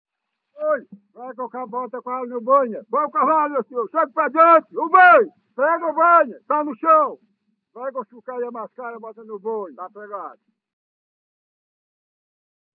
Aboio (falado)